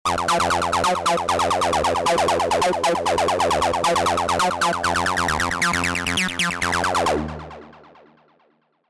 1. Línea de bajo ‘Acid’
Aunque ya era popular antes de la llegada del sonido ‘Rave’, esta tendencia los asimiló con un enfoque más agresivo y distorsionado, llevando estos sonidos un paso más allá de lo que ya habíamos podido oír tras el boom del acid house.
Este sonido retorcido y resonante nació en los circuitos de la famosa Roland Tb-303, y aunque esta máquina y sus clones y emulaciones siguen siendo la forma más rápida y fiel de recrear el ‘acid’, podemos hacerlo también de forma muy fácil con cualquier sintetizador que disponga de osciladores con dientes de sierra y filtros resonantes.Primero, vamos a escuchar lo que vamos a crear en este ejemplo:
Audio-1-How-to-make-classic-rave-sounds-on-Ableton-Live-2.mp3